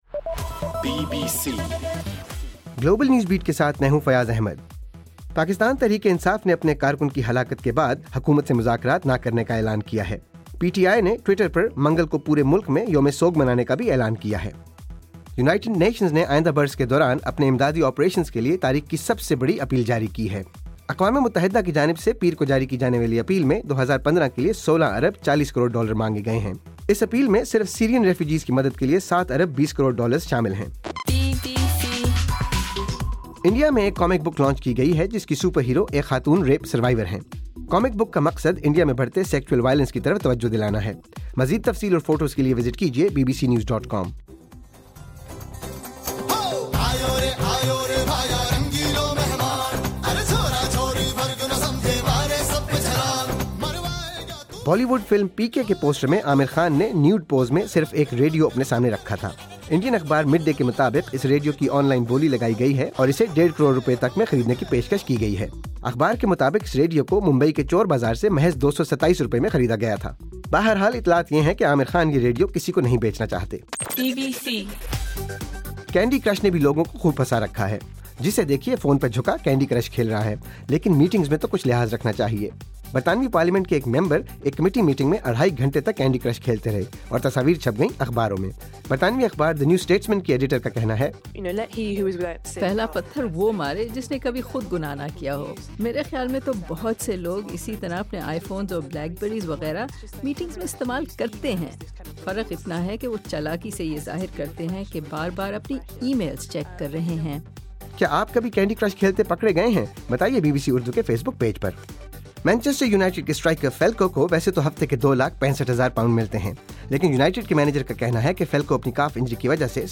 دسمبر 8: رات 12 بجے کا گلوبل نیوز بیٹ بُلیٹن